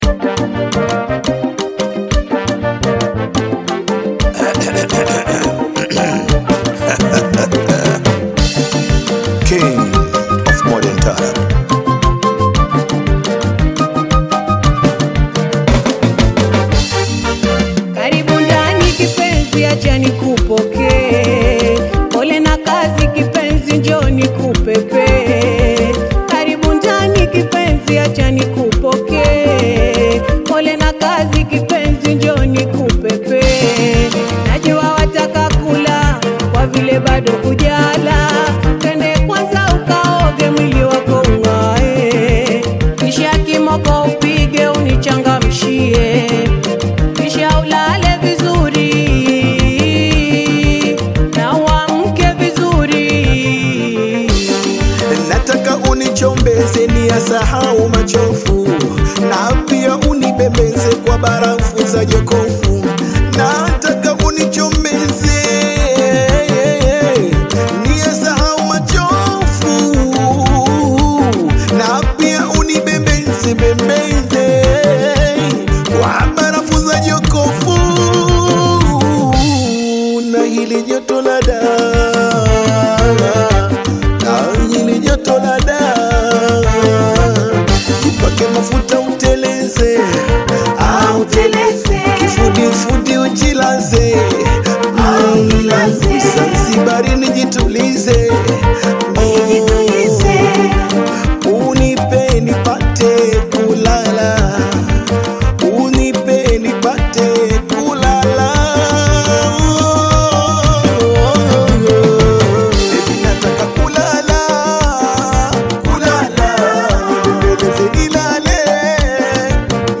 Tanzanian Taarab singer and songwriter
Taarab You may also like